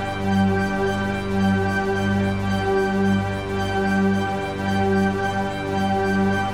Index of /musicradar/dystopian-drone-samples/Tempo Loops/110bpm
DD_TempoDroneD_110-G.wav